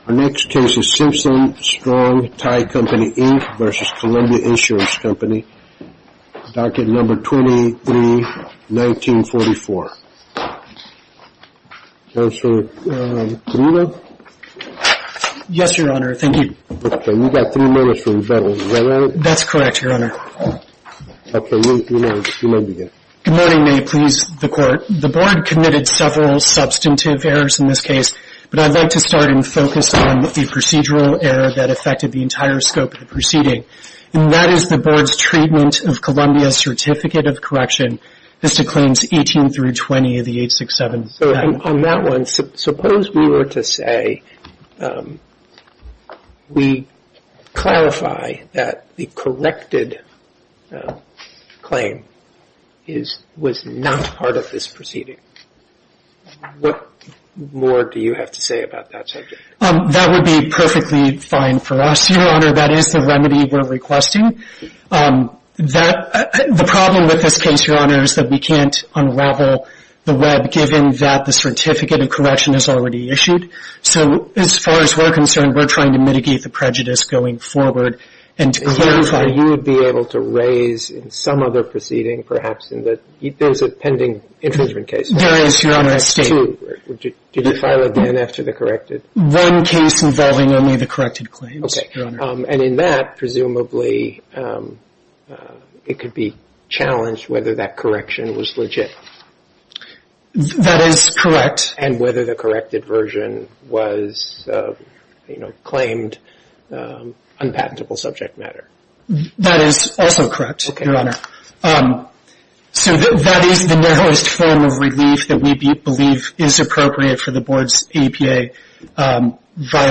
Simpson Strong-Tie Company Inc. V. Columbia Insurance Company Oral Arguments For The Court Of Appeals For The Federal Circuit podcast